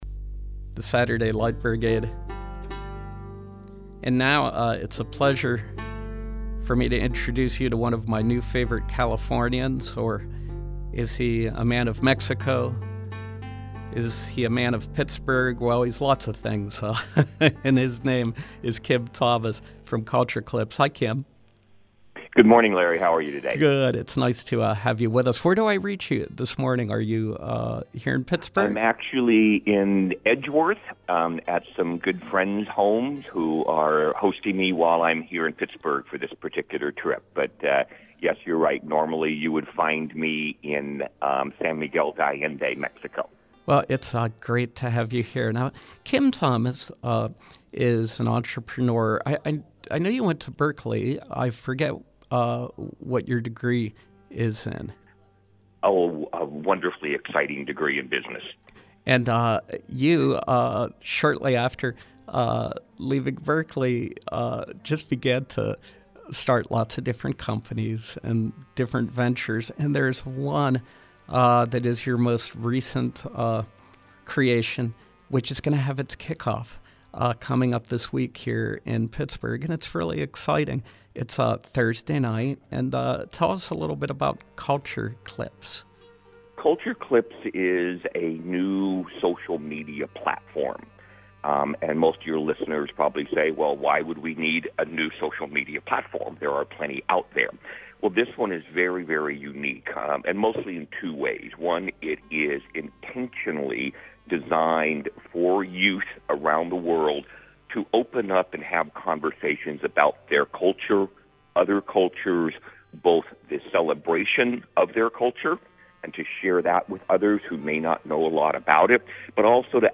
Home » Interviews